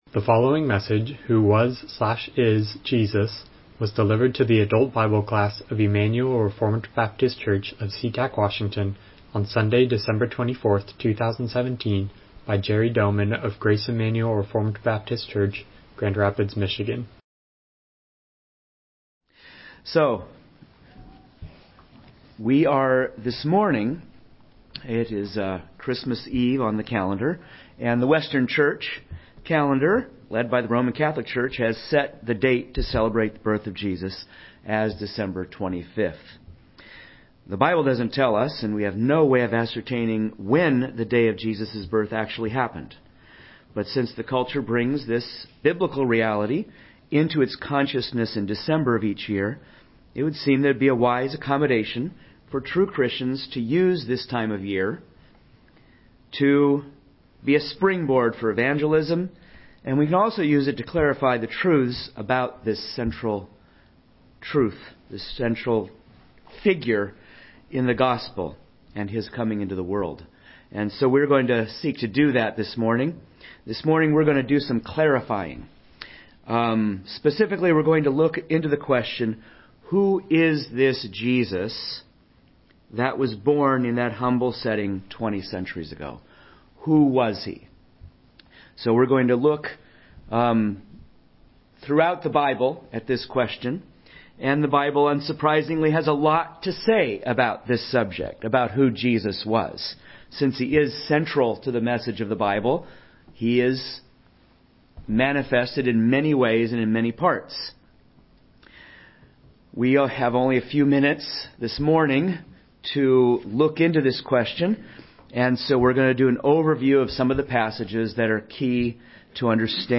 Miscellaneous Service Type: Sunday School « Gospel Preaching